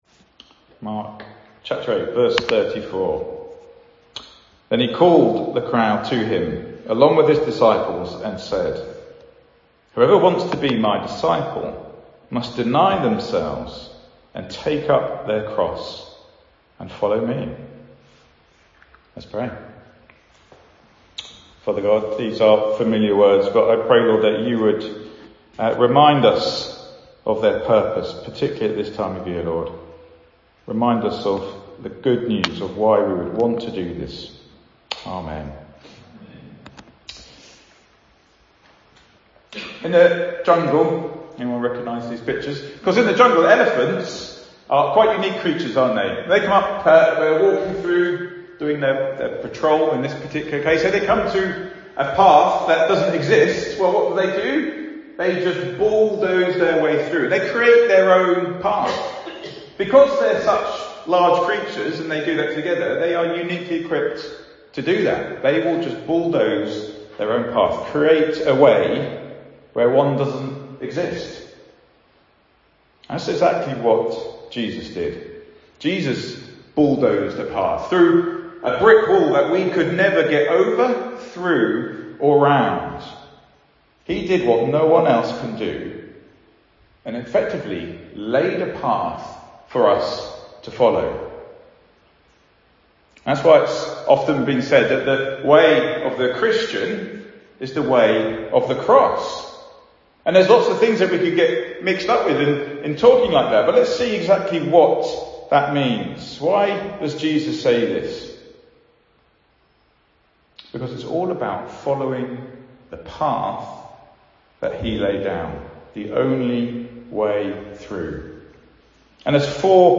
Good Friday Service
A short service with communion, and then time to chat over teas and coffees afterwards